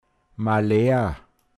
pinzgauer mundart
Malheur Maleer, n.